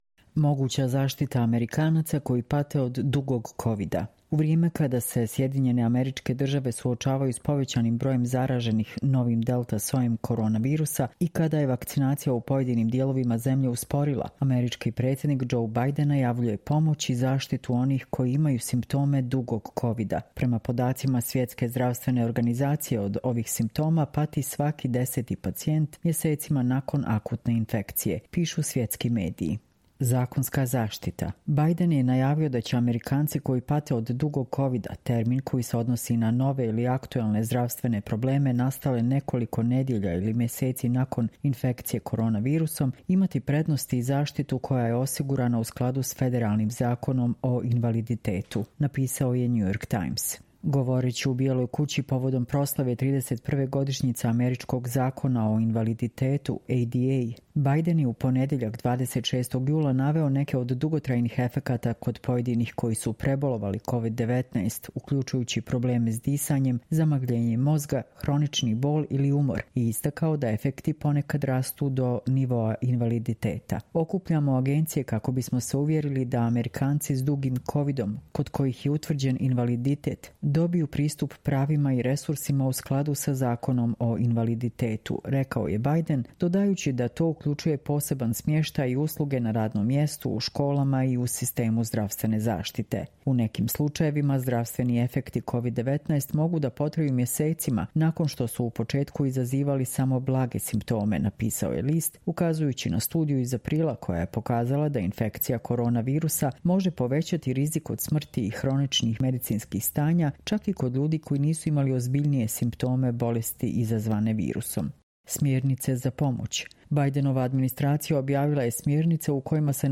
Čitamo vam: Moguća zaštita Amerikanaca koji pate od ‘dugog kovida’